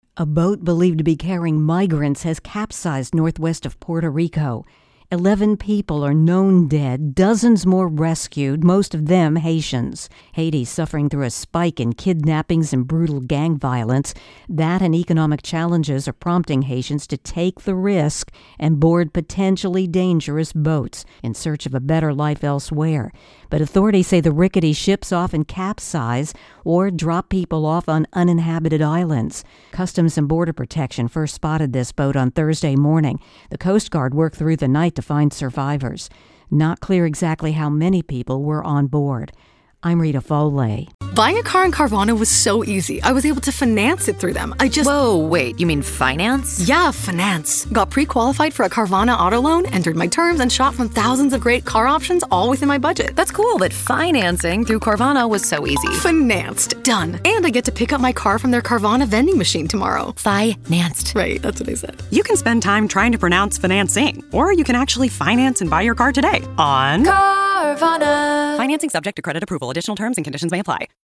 Puerto Rico Capsized Boat Intro+Voicer